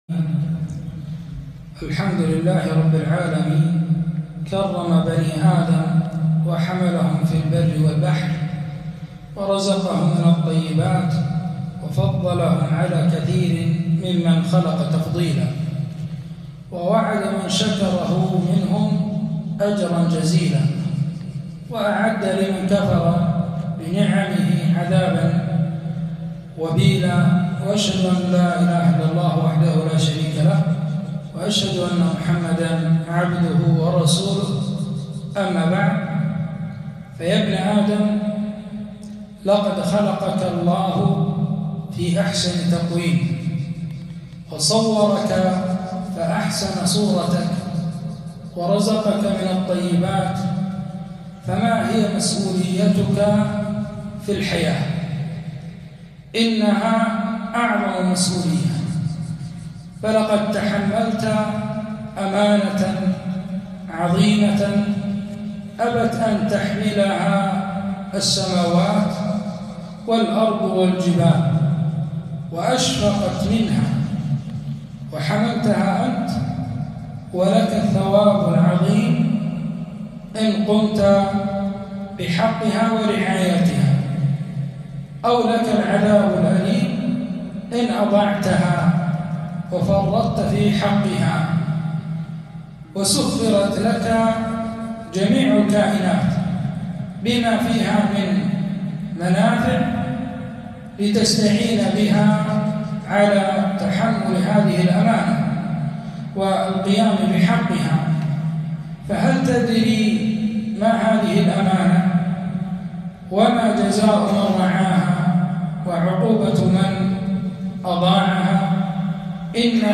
محاضرة - مسؤولية الإنسان المؤمن في الحياة